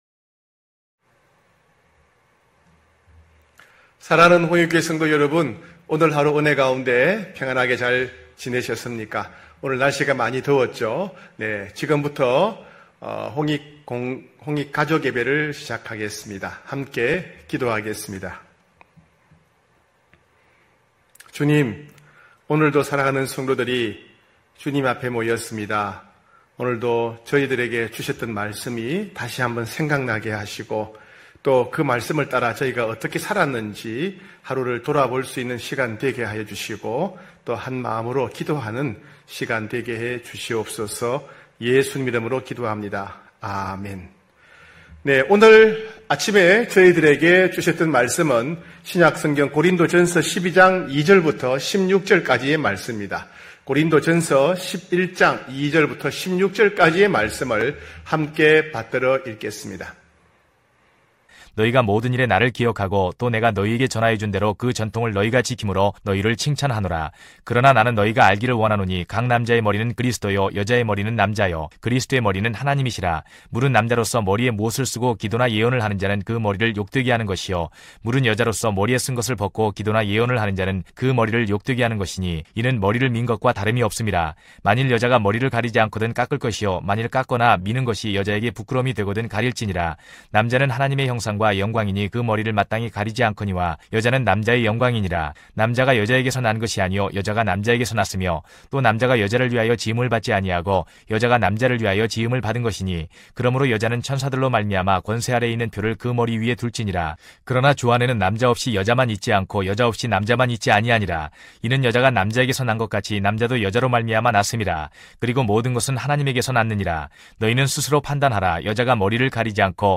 9시홍익가족예배(6월17일).mp3